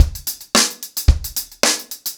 TupidCow-110BPM.23.wav